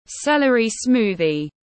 Sinh tố cần tây tiếng anh gọi là celery smoothie, phiên âm tiếng anh đọc là /ˈsel.ər.i ˈsmuː.ði/
Celery smoothie /ˈsel.ər.i ˈsmuː.ði/